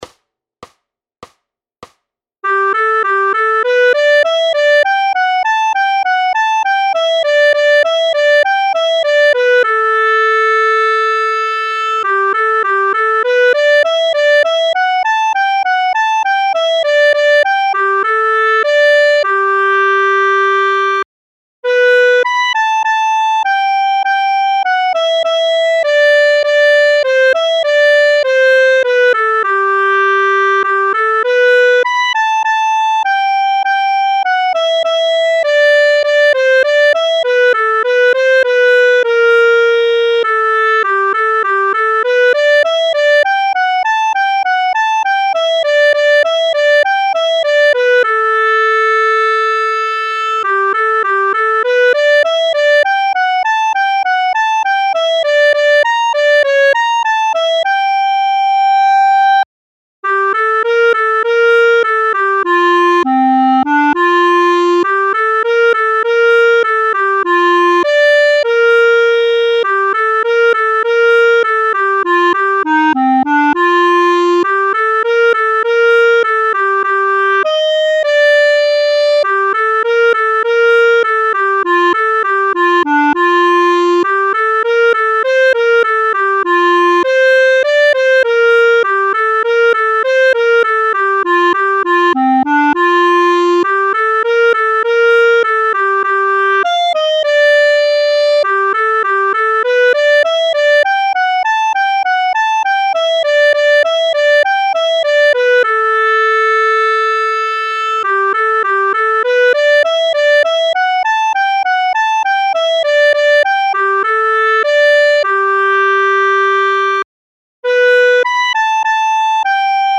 Noty na klarinet.
Formát Klarinetové album
Hudební žánr Klasický